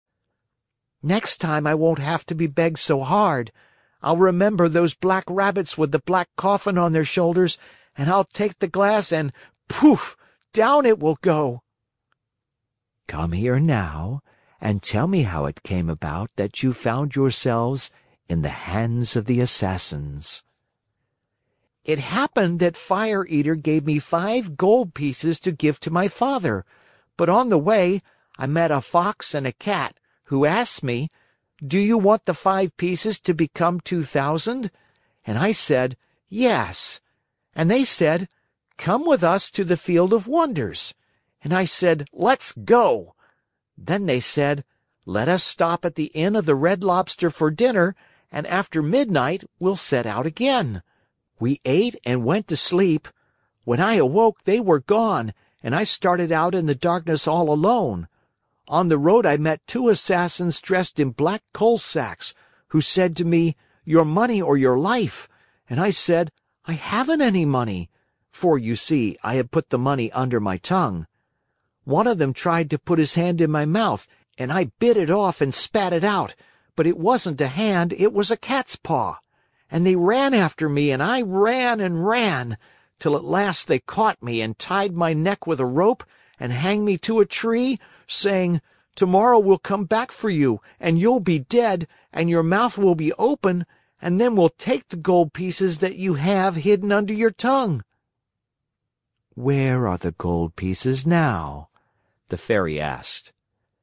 在线英语听力室木偶奇遇记 第47期:说谎长了长鼻子(4)的听力文件下载,《木偶奇遇记》是双语童话故事的有声读物，包含中英字幕以及英语听力MP3,是听故事学英语的极好素材。